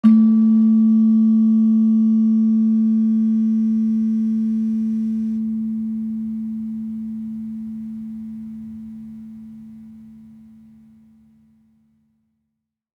Sound Banks / HSS-Gamelan-1 / Gender-1 / Gender-1-A2-f.wav
Gender-1-A2-f.wav